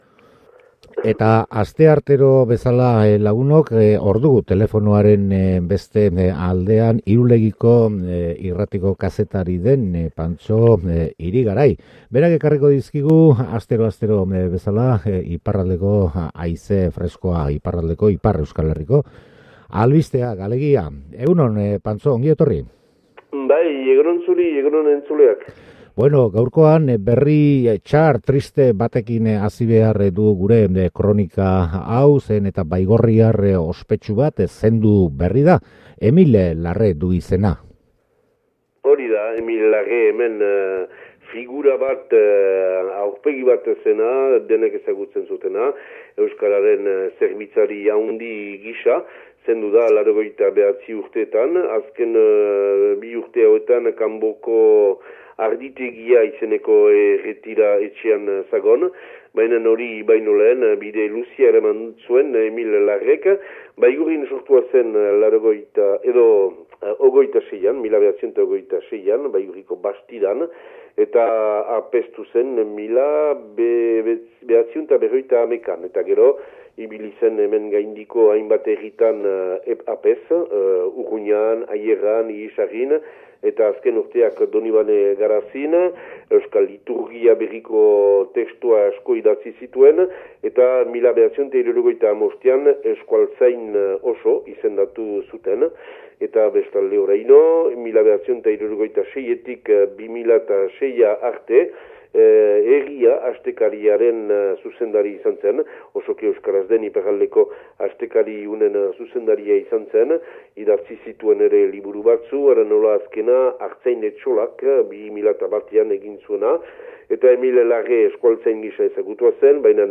Ipar Euskal Herriko kronika